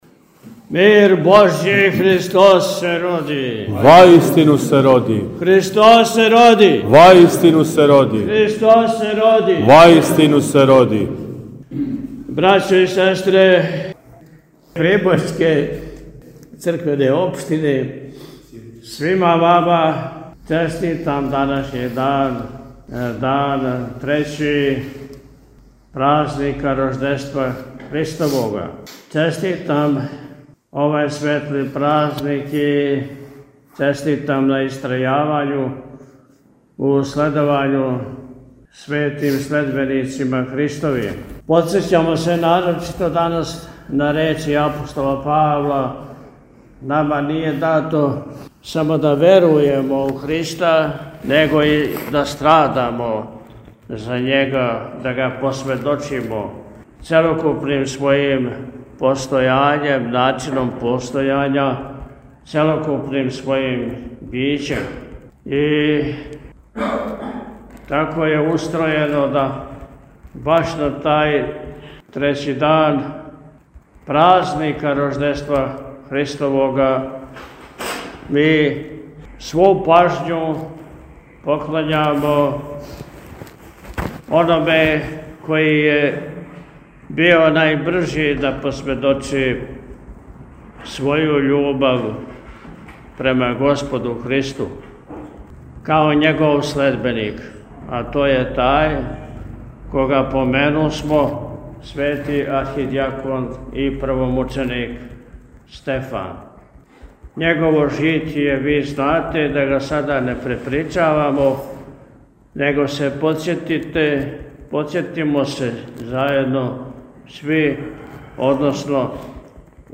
На празник Светог првомученика и архиђакона Стефана, у четвртак 9. јануара 2025. године, Његово Високопреосвештенство Архиепископ и Митрополит милешевски г. Атанасије служио је Свету архијерејску Литургију у храму Светог кнеза Лазара у Старом Прибоју.
Честитајући сабранима трећи дан празника Рождества Христовога Високопреосвећени је у пастирској беседи рекао: – Честитам на истрајавању у следовању светим следбеницима Христовим.
Priboj-Stevanjdan.mp3